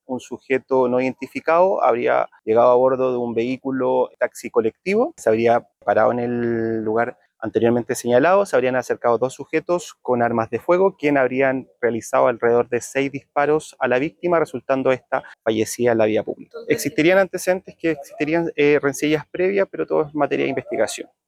fiscal-2.mp3